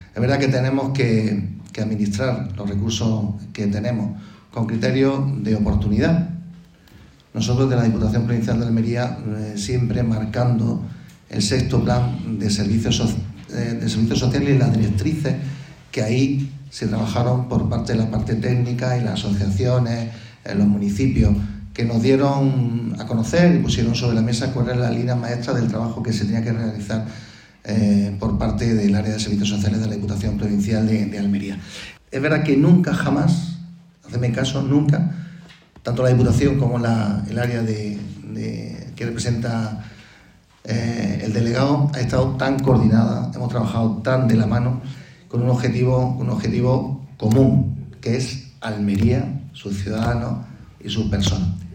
Así, la el Centro Asociado de la UNED en Almería ha acogido el III Foro de Innovación Social, que ha contado con ponencias y mesas redondas para compartir experiencias entre todos los asistentes.
El vicepresidente de la Diputación y diputado de Bienestar Social, Ángel Escobar, y el delegado de Inclusión Social, Juventud, Familias e Igualdad, Francisco Bellido, han clausurado esta tercera edición.